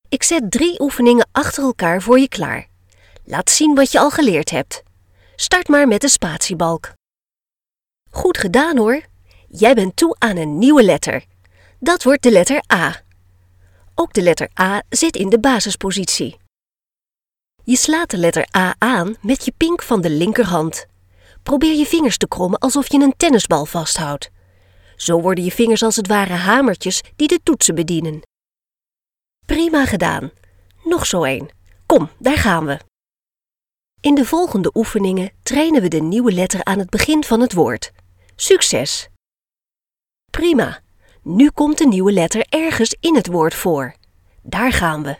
Female
Adult (30-50)
Young mature, clear, soprano, without regional accent.
Versatile - Vast range of voice styles available.
Main Demo